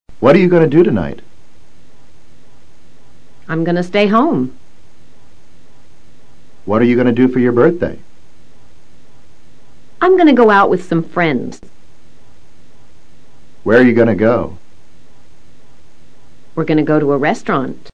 Pronunciación reducida de GOING TO
Ten presente que en las conversaciones informales la expresión GOING TO suele pronunciarse en forma reducida.